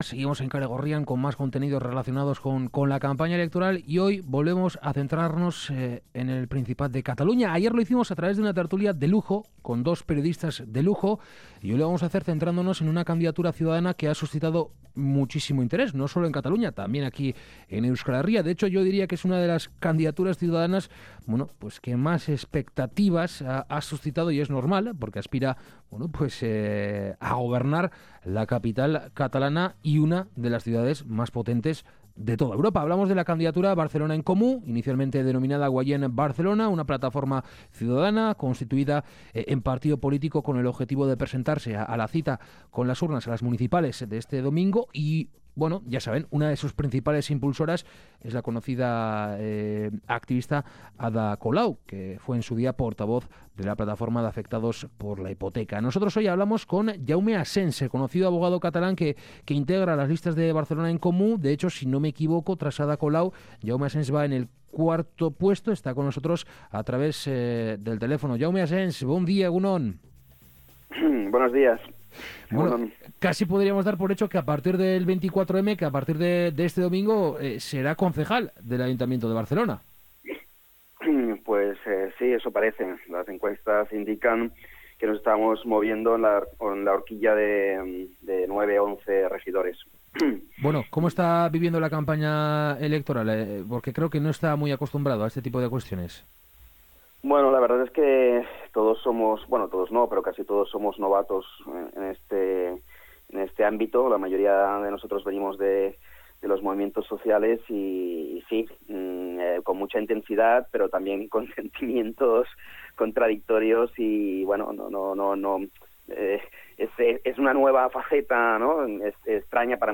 Sobre esta candidatura electoral hemos charlado con Jaume Asens, conocido abogado catalán que integra las listas de Barcelan en Comú.